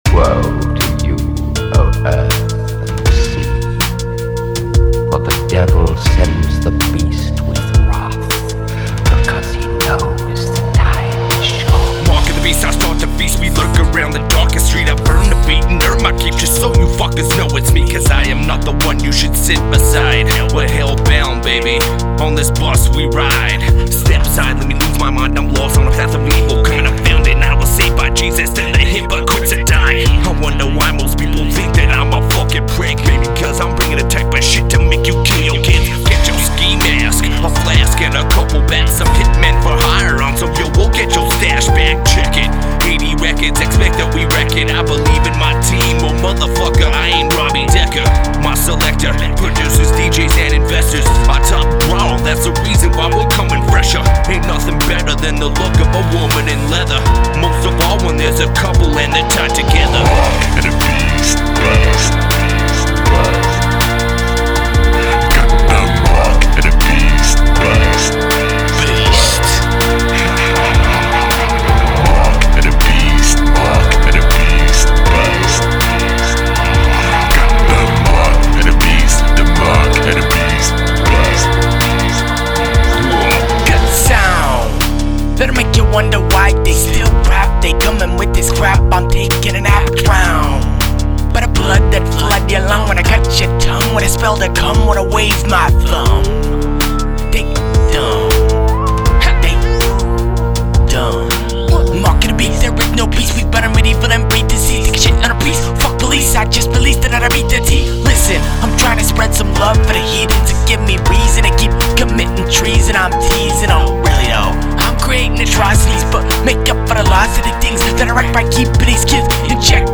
energetic tracks with dark undertones
real hip hop from the heart
Recorded at Ground Zero Studios